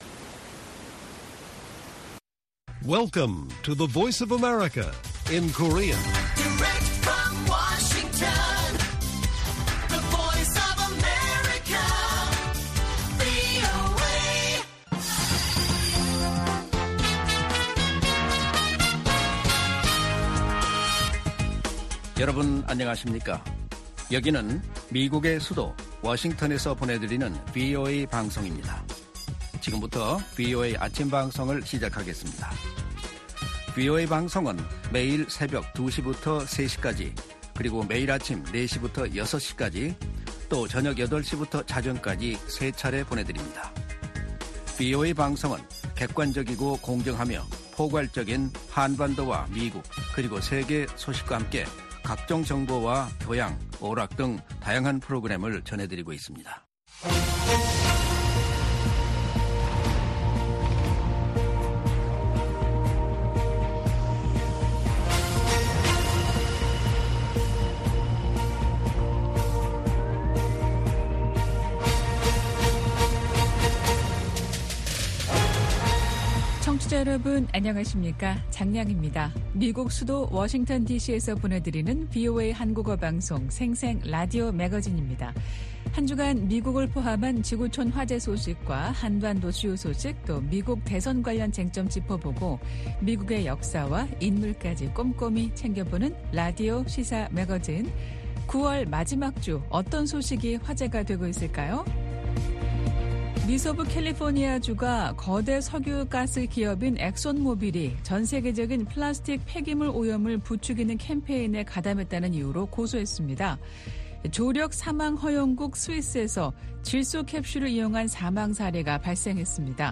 VOA 한국어 방송의 월요일 오전 프로그램 1부입니다. 한반도 시간 오전 4:00 부터 5:00 까지 방송됩니다.